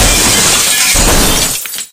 Crash.ogg